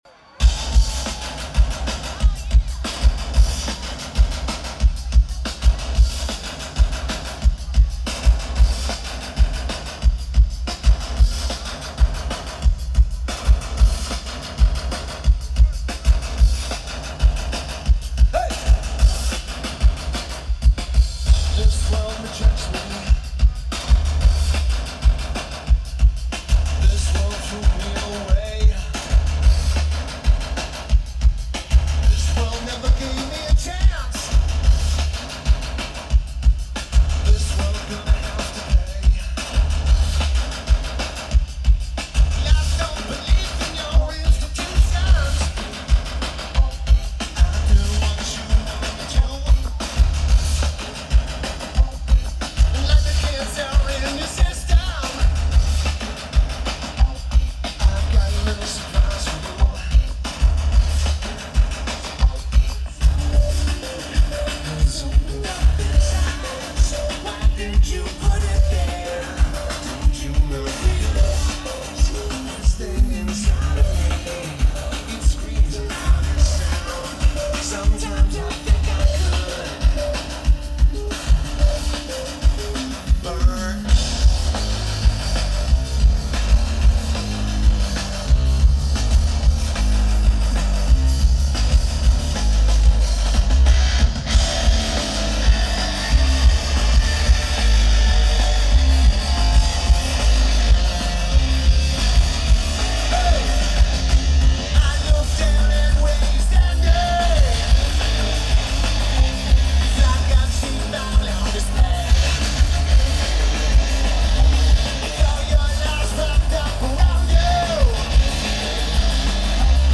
Welcome To Rockville